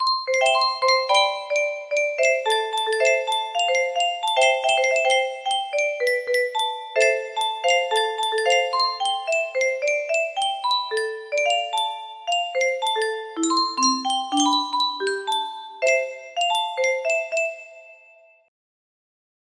Clone of Unknown Artist - Untitled music box melody
Grand Illusions 30 (F scale)